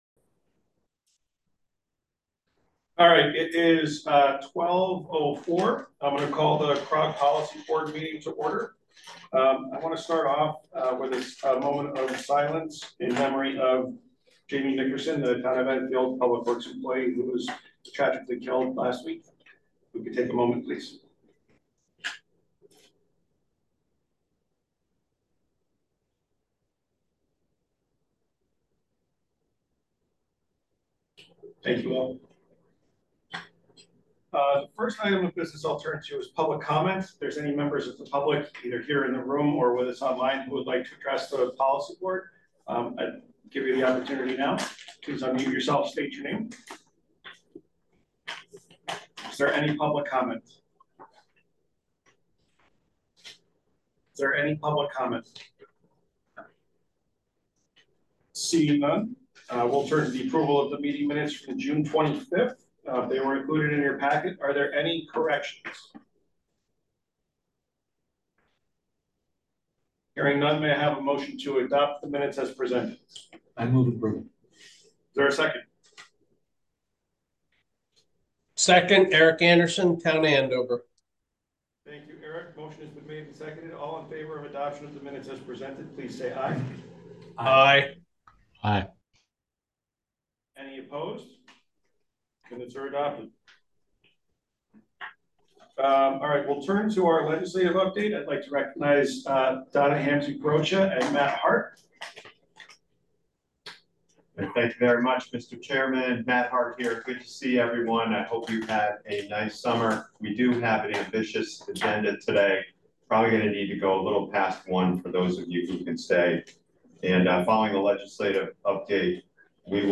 This is a HYBRID meeting.